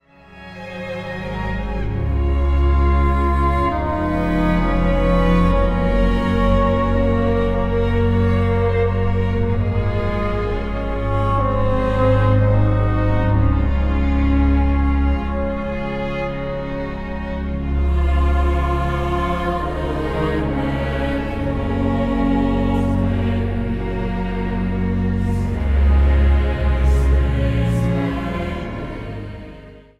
orgel
Zang | Jongerenkoor